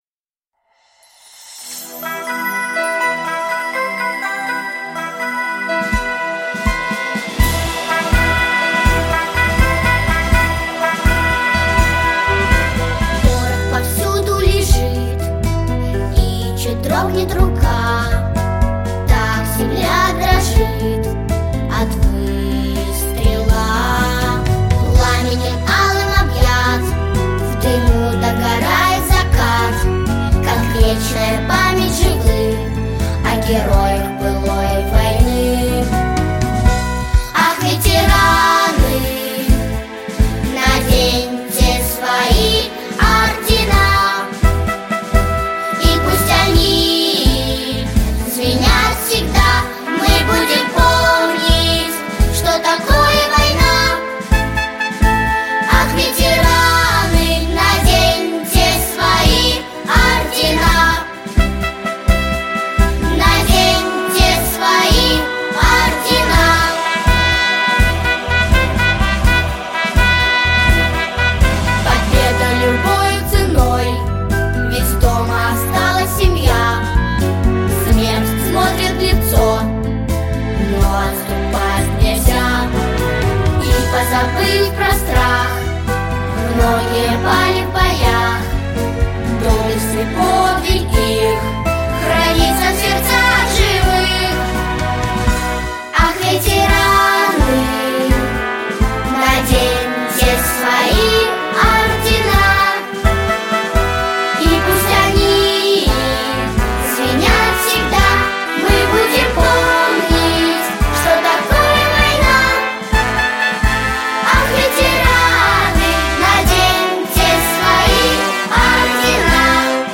🎶 Детские песни / Песни на праздник / День Победы🕊